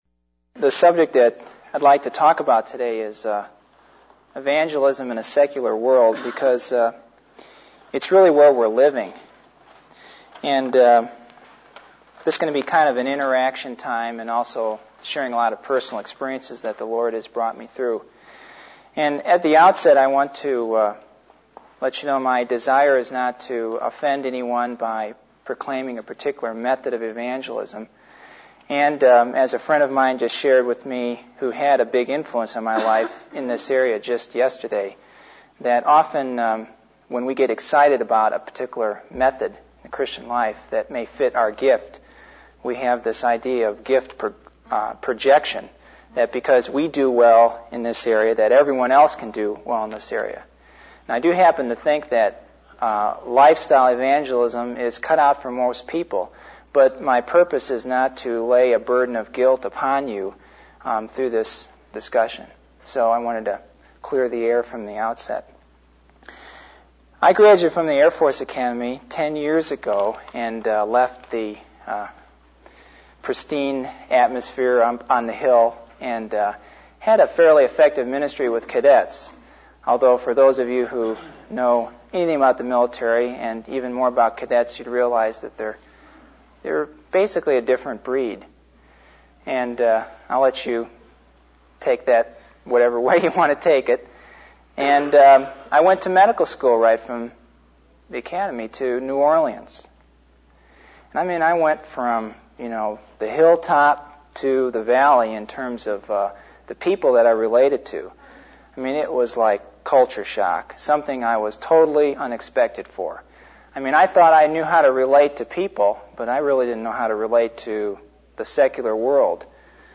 In this sermon, the speaker addresses the issue of Christians becoming too isolated from the world. He emphasizes the importance of being separated from the world but not isolated, and highlights the danger of being too involved in the Christian subculture.